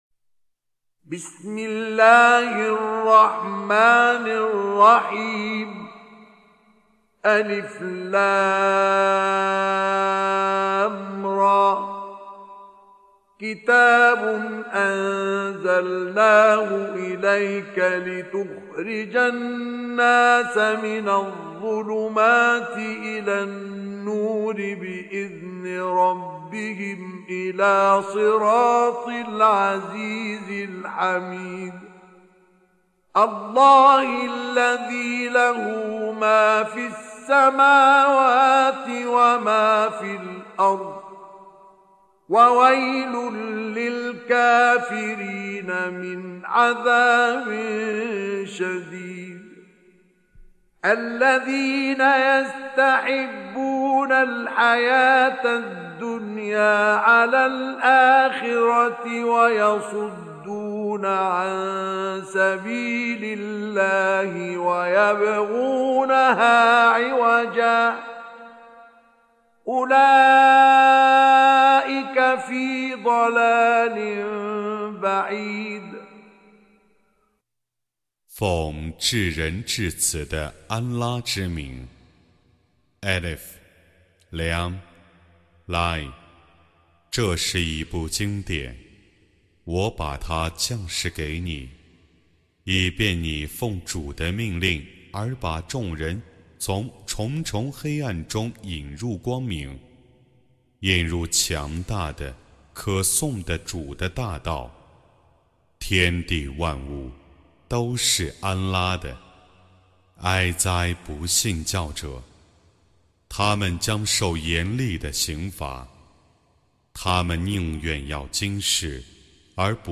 Surah Sequence تتابع السورة Download Surah حمّل السورة Reciting Mutarjamah Translation Audio for 14. Surah Ibrah�m سورة إبراهيم N.B *Surah Includes Al-Basmalah Reciters Sequents تتابع التلاوات Reciters Repeats تكرار التلاوات